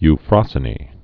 (y-frŏsə-nē)